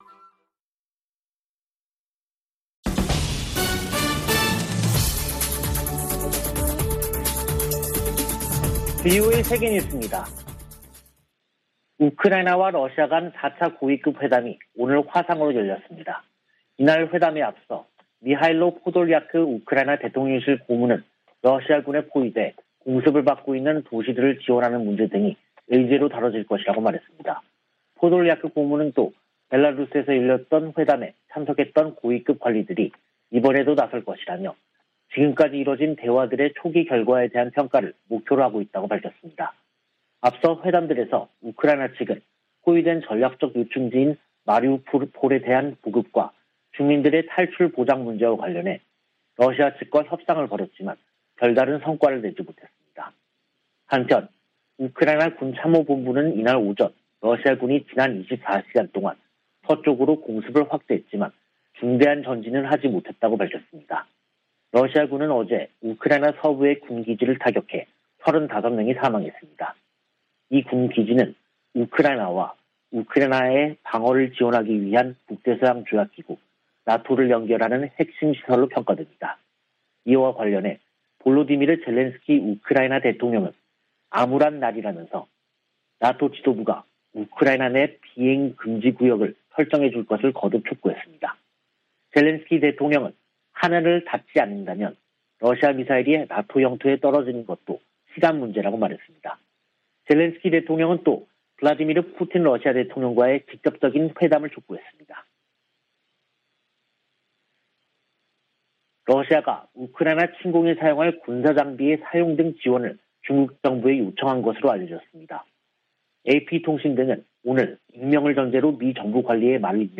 VOA 한국어 간판 뉴스 프로그램 '뉴스 투데이', 2022년 3월 14일 2부 방송입니다. 성 김 미 대북특별대표가 중국에 북한이 도발을 중단하고 대화에 복귀하도록 영향력을 발휘해 달라고 요구했습니다. 미 국무부는 북한 탄도미사일 발사 등이 역내 가장 긴박한 도전이라고 지적하며 한국 차기 정부와의 협력을 기대했습니다. 미-한 군 당국은 북한이 신형 ICBM 성능 시험을 위한 추가 발사를 준비하는 징후를 포착하고 대비태세를 강화하고 있습니다.